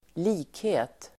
Uttal: [²l'i:khe:t]